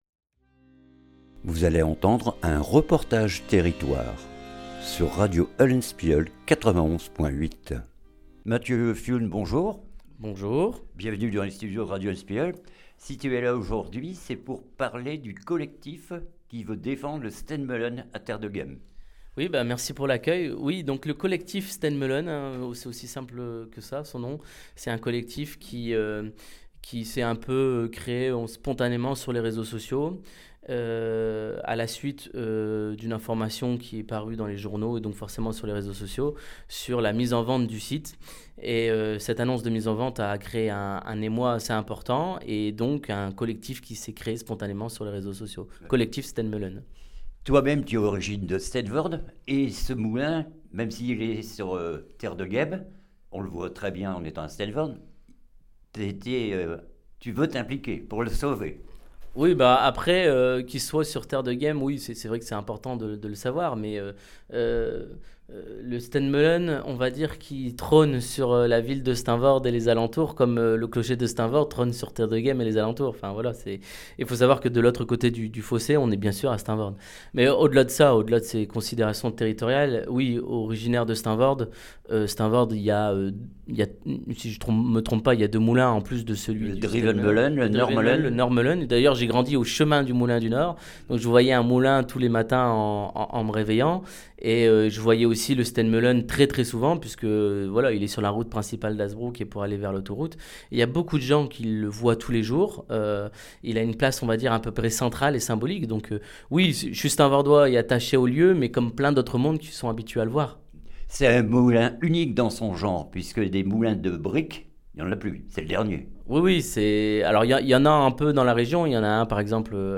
REPORTAGE TERRITOIRE STEEN MEULEN